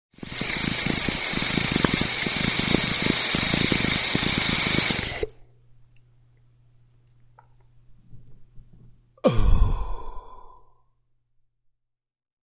Звуки кальяна
Шорох раскаленного угля в кальяне